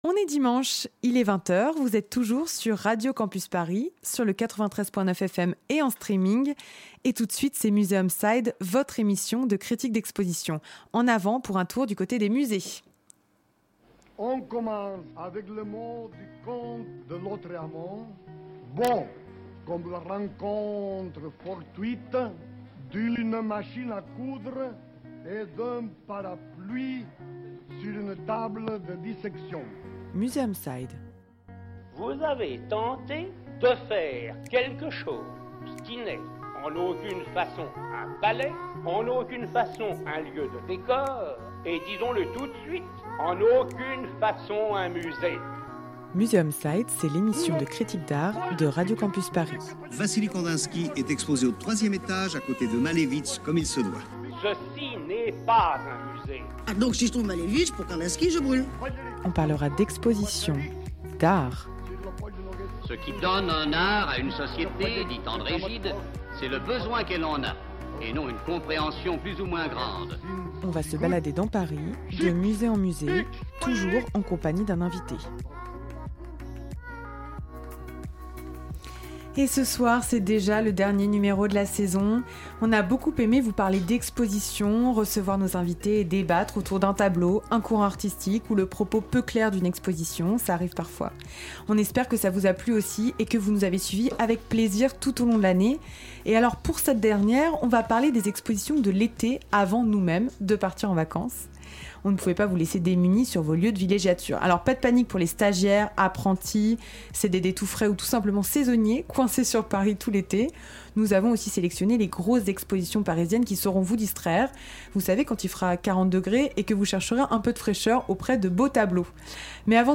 Cette saison, avec Museum side, on vous emmène parcourir la capitale pour dénicher pour vous les expositions qui valent le coup d’œil ou celles qui au contraire sont un peu décevantes. Le principe : deux expositions à l’affiche dans de grands musées parisiens sur lesquelles nous débattrons avec à chaque fois un invité spécialiste qui nous donnera des clés de compréhension.